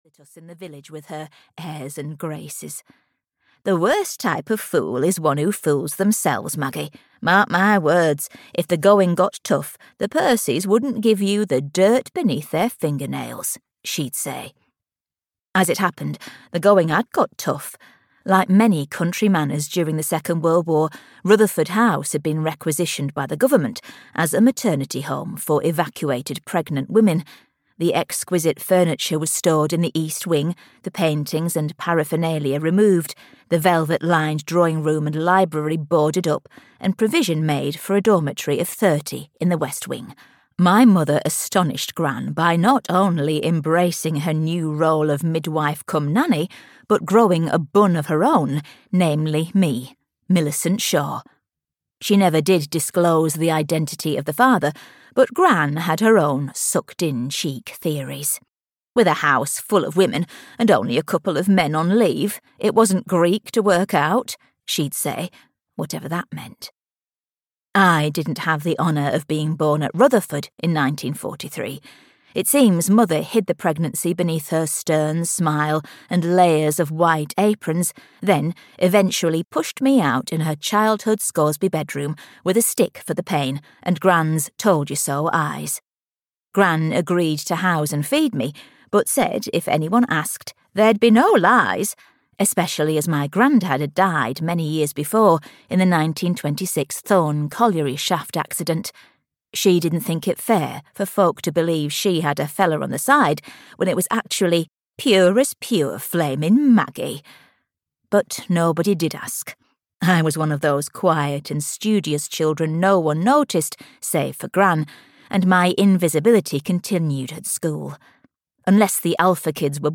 The Shadows of Rutherford House (EN) audiokniha
Ukázka z knihy